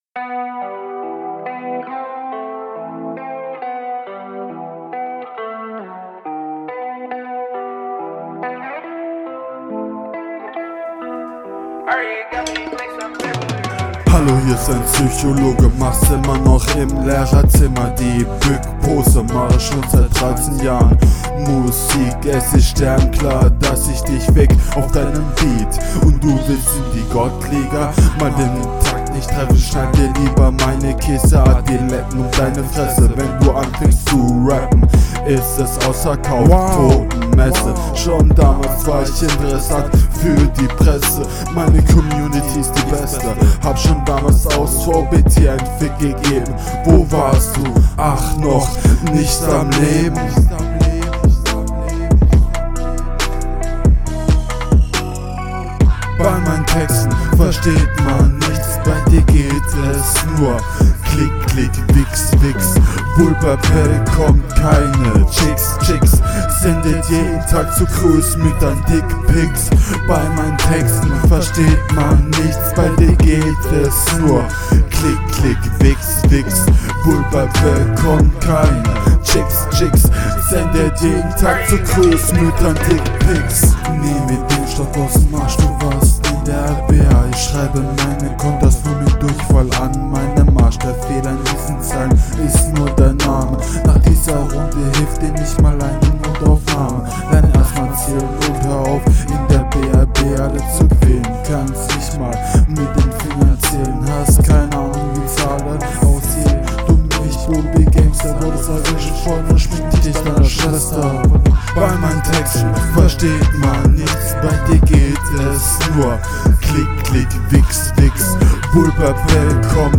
Man versteht dich besser als dein Gegner.